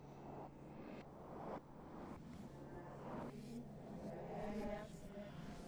I am more concerned with impercetible sounds in my recordings and this was my focus during the workshop, although I did record some sounds in the stairwell and then some smaller sounds.
ste-032-reverse.wav